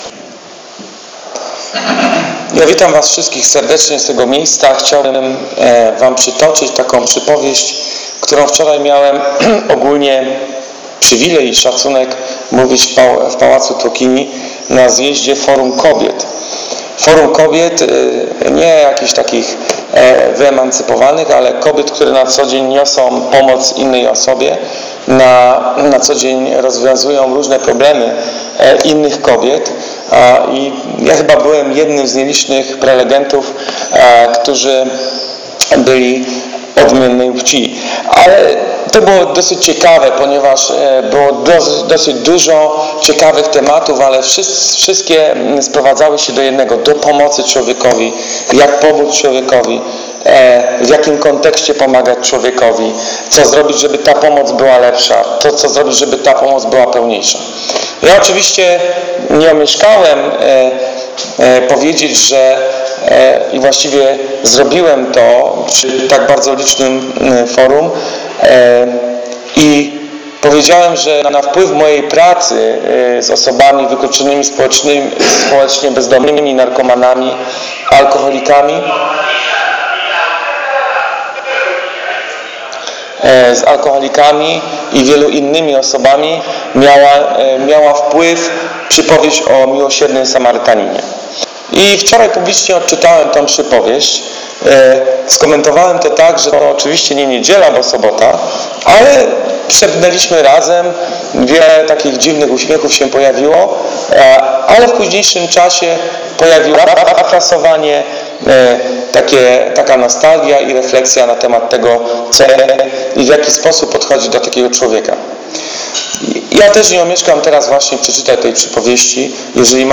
Czego możemy nauczyć się od Samarytanina? Czy ta przypowieść jest nadal aktualna dla Nas? W jaki sposób możemy ją zastosować w pracy duszpasterskiej z osobami wykluczonymi?Zachęcam do przeczytania, posłuchania wczorajszego kazania.